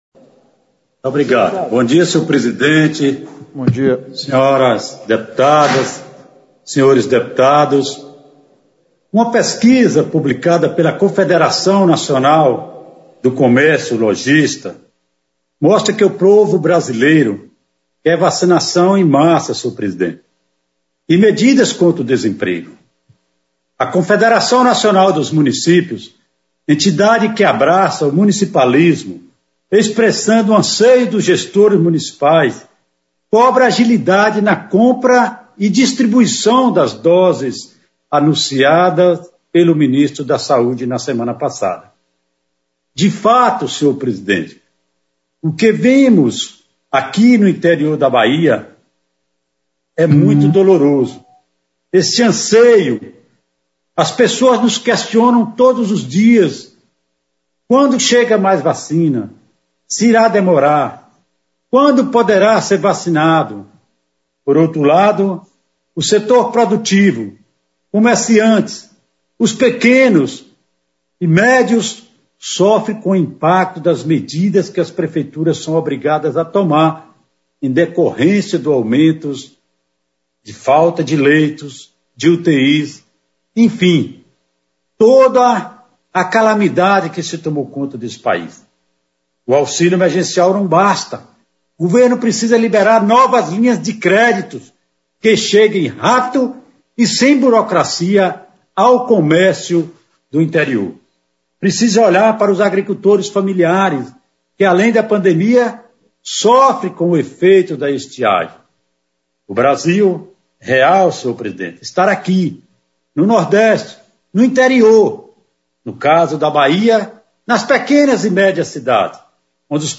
O Deputado Federal Charles Fernandes tem usado com frequência no decorrer dessa semana a tribuna da Câmara dos Deputados, através de sessão remota para reforçar a necessidade da agilidade da vacinação contra a covid-19 em massa para a população.
PRONUNCIAMENTO.mp3